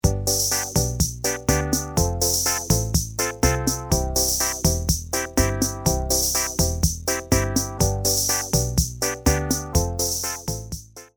Rumba demo